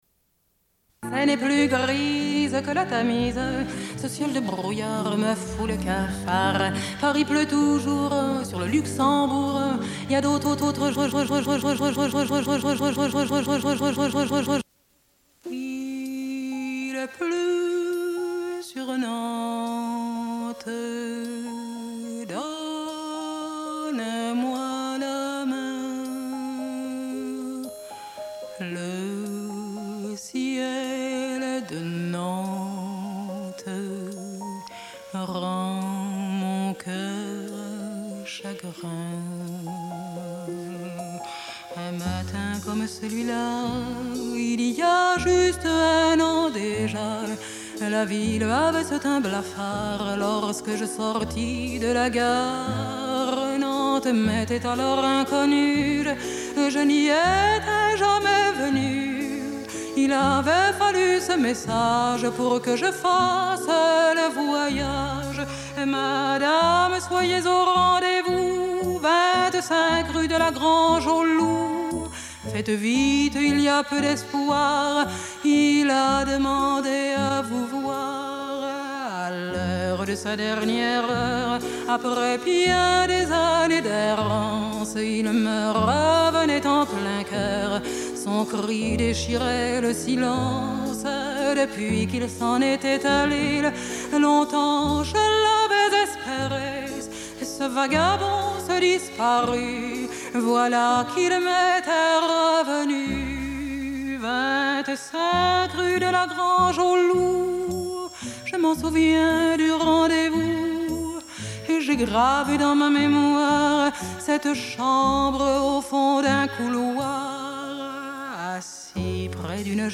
Une cassette audio, face A
Radio